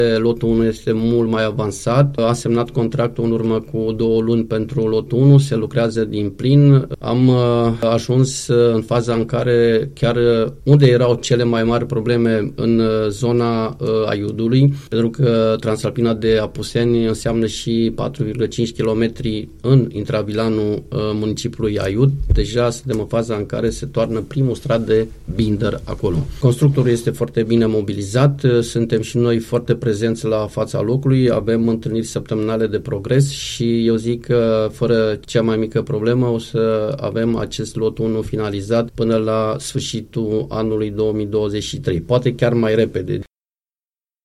Lucrările pe acest lot sunt avansate, a declarat, la Unirea FM, vicepreședindetele CJ Alba, Marius Hațegan. Acesta a precizat că lucrările de pe lotul 1 vor fi finalizate până la sfârșitul anului 2023.